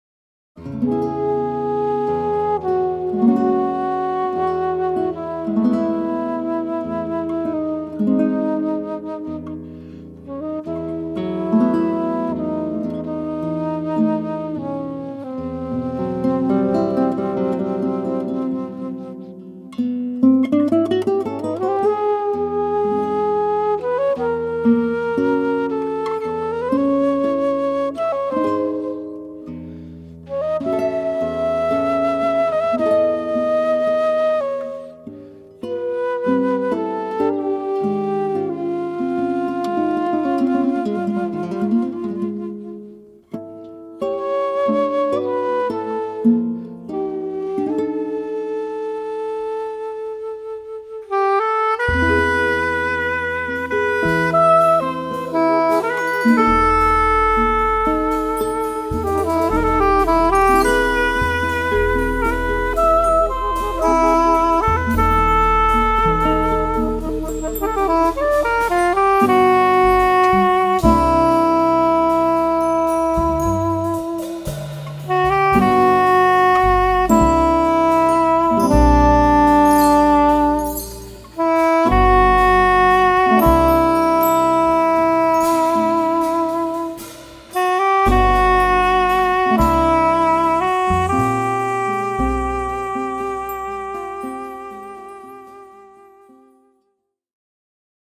guitar
alto/soprano saxophones, flutes
tenor/soprano saxophones
piano
bass
drums
percussion